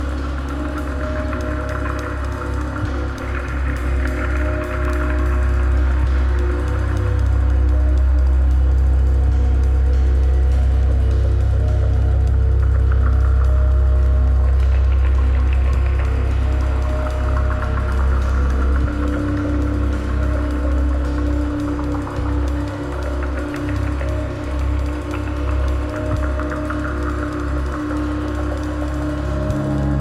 New Release Experimental Jazz